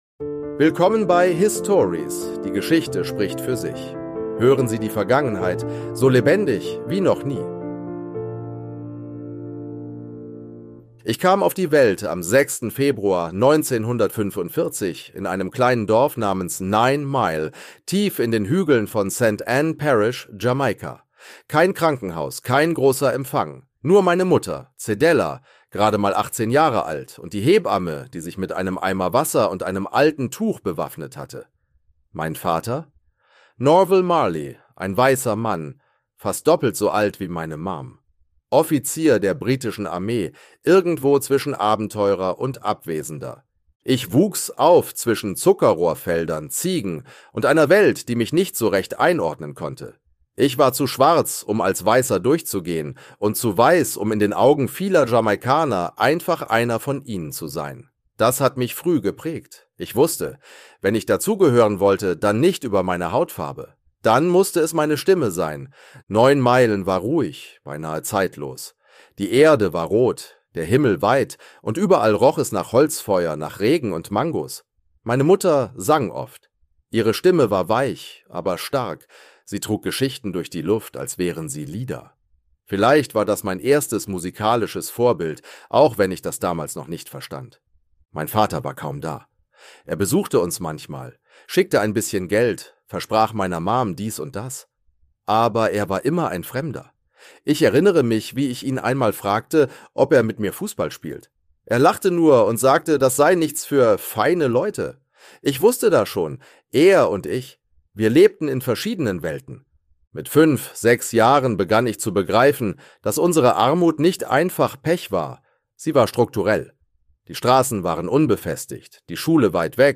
Er war mehr als ein Musiker – er war eine Stimme für Freiheit, Liebe und Gerechtigkeit. In dieser Episode erzählt Bob Marley selbst von seiner Kindheit in Jamaika, dem Aufstieg mit den Wailers, der spirituellen Kraft des Reggae und den Kämpfen seines Lebens.